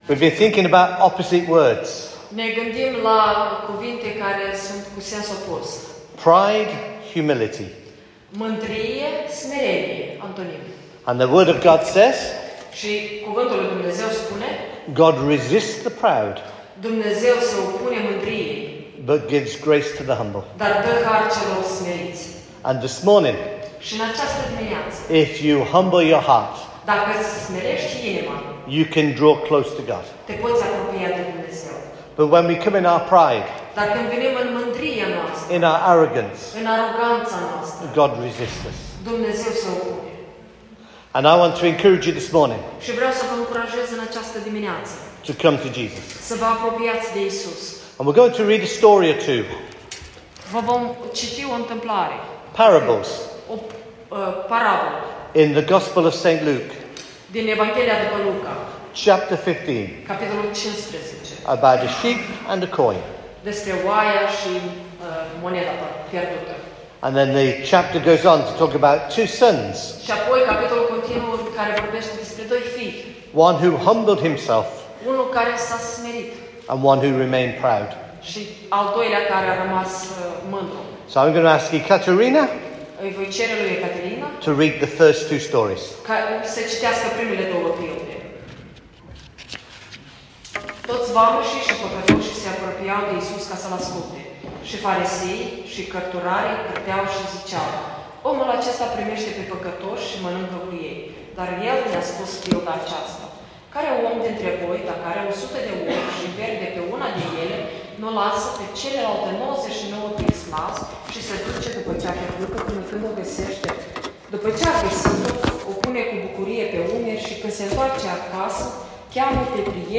sermon
with Romainian translation